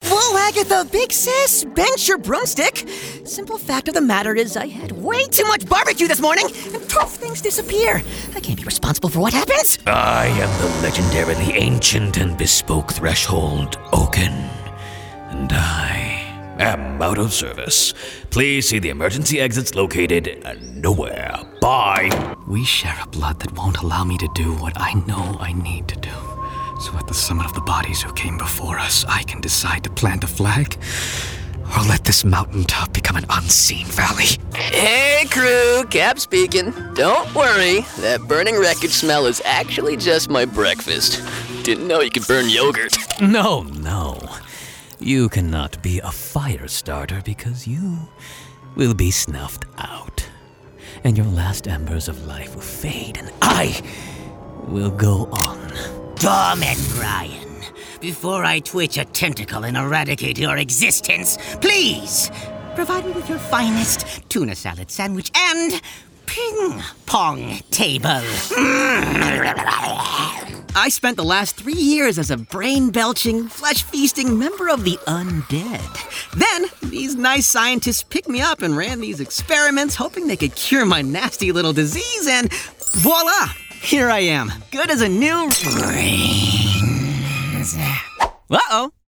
Voiceover : Commercial : Men